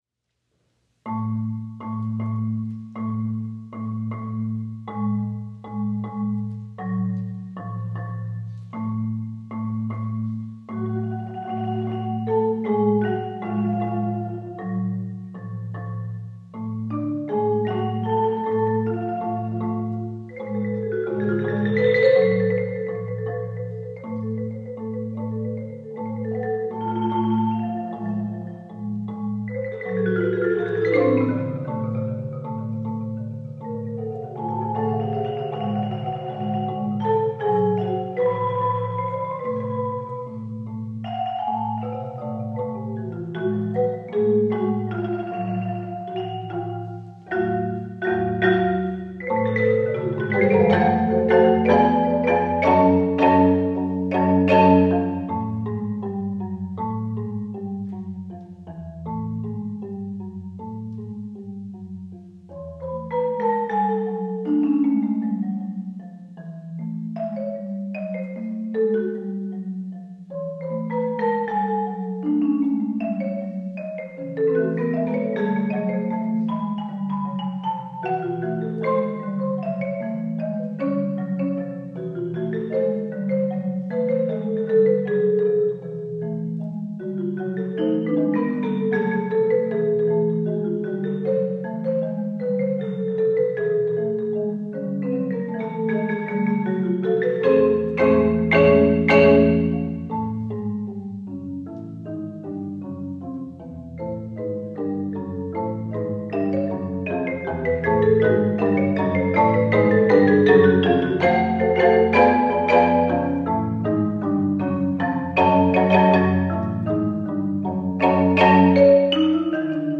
Voicing: Marimba Quartet